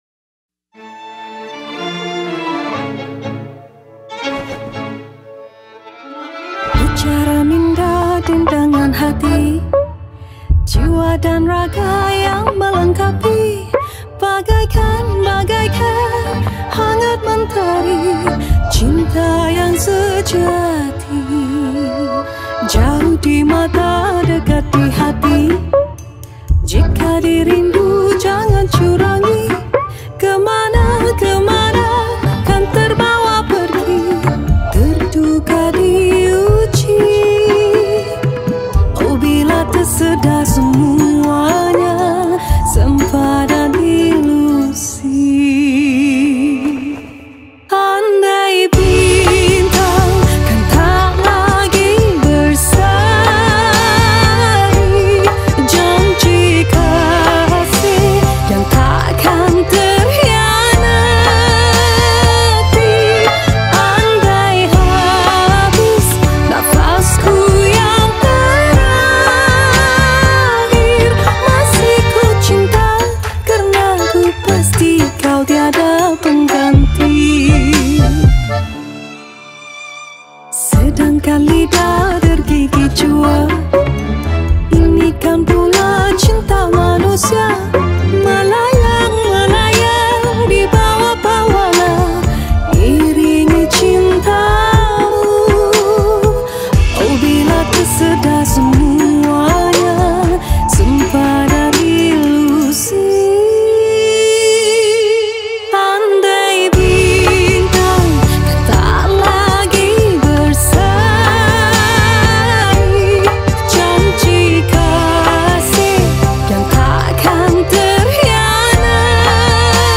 Malay Songs